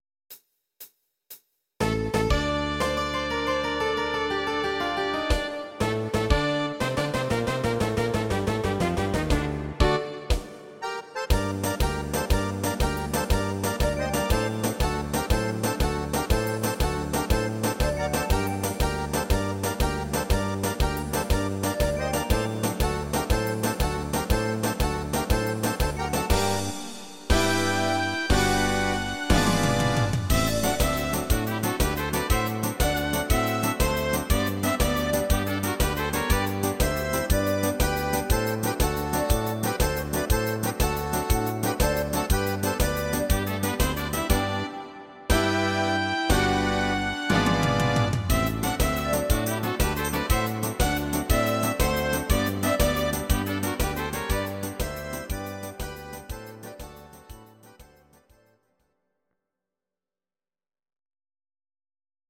Audio Recordings based on Midi-files
German, Traditional/Folk, Volkst�mlich